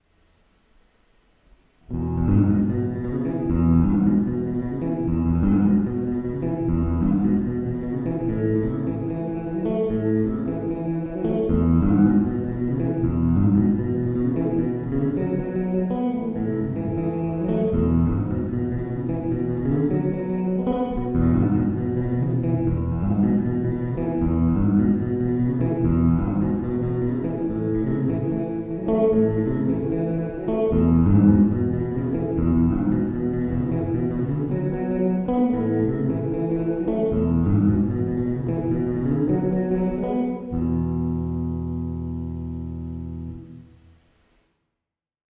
For country, the studio will or at least put out a tone you’ll be happy with.
Here’s something I just recorded last night on the studio.